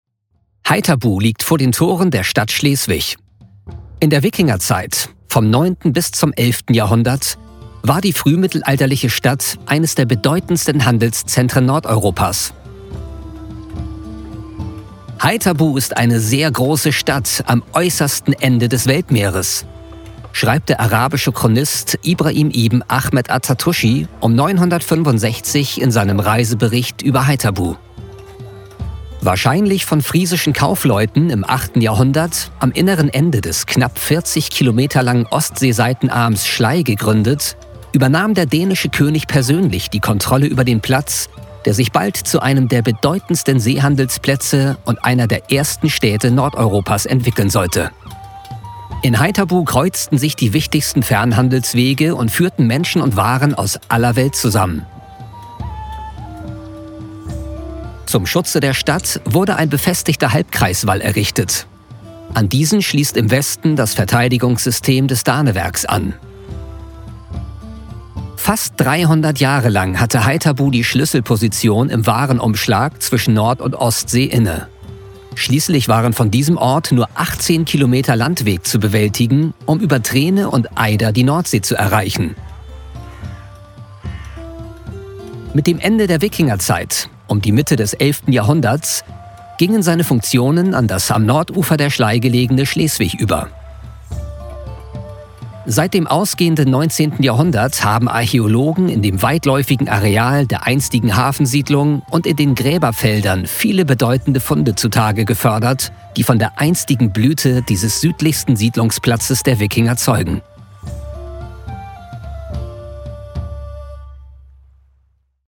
Commerciale, Cool, Mature, Amicale, Corporative
Guide audio